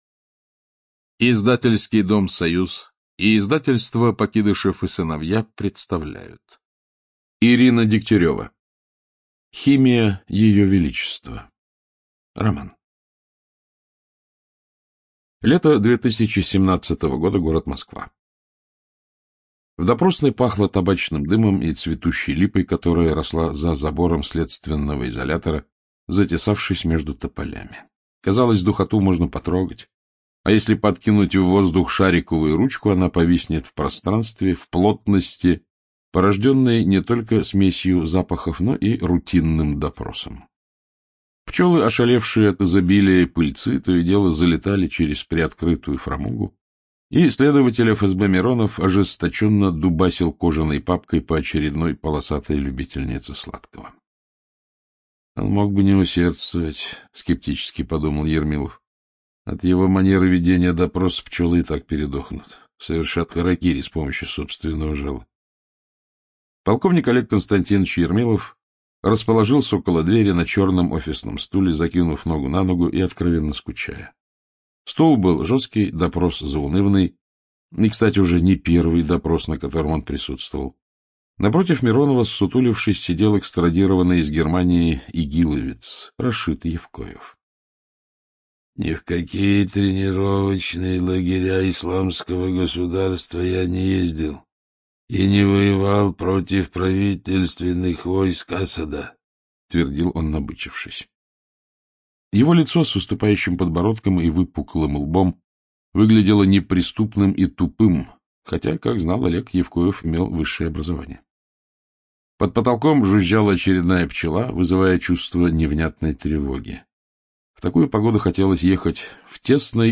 ЧитаетКлюквин А.
Студия звукозаписиСоюз